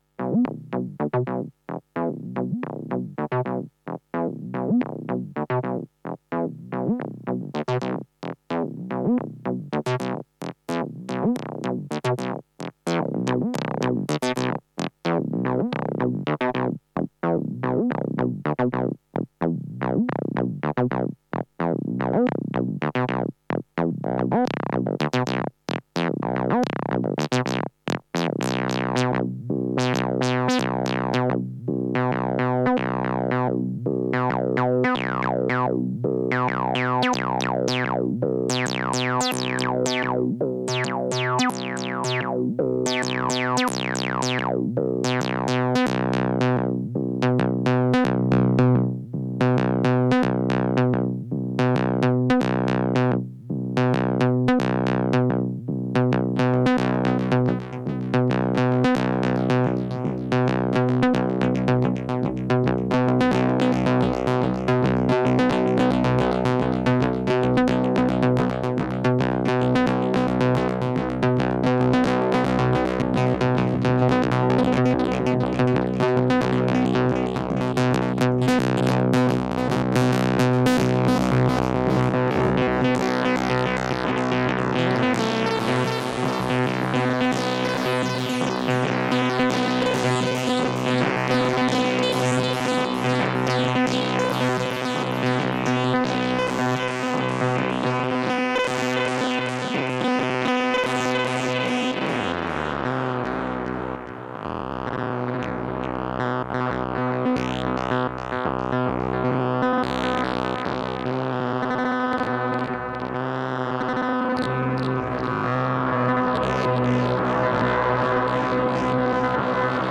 Messing around with fx loops on the Octatrack (engaged at 0:56) using a Red Panda Particle and Tensor for some pitch shifting and then into a Thru track with phaser on fx1 and a delay on full wet (no dry mix) on fx2.
Wide and “dark” setting on the phaser and ping pong on the delay with an LFO modulating the cutoff/width of the repeats. Gets a little spacetrippy towards the end.